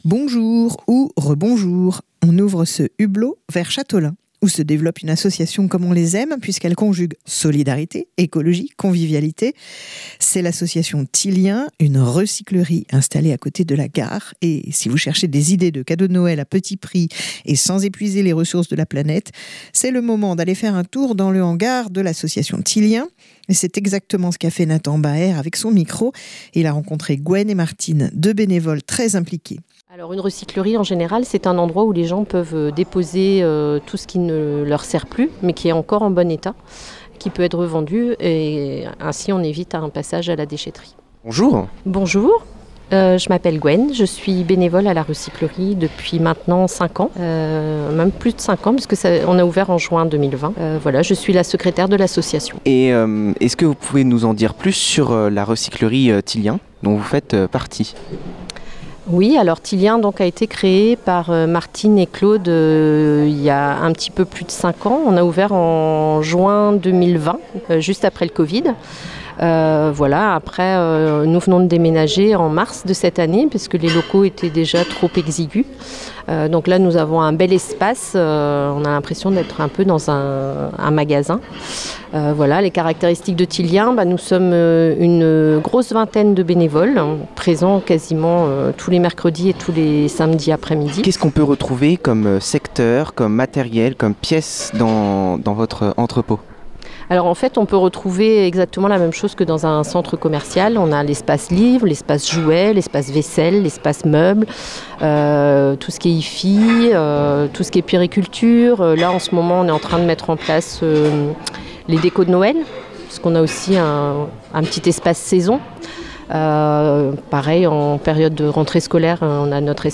Deux bénévoles nous font découvrir le nouveau magasin et nous expliquent le fonctionnement de cette association généreuse.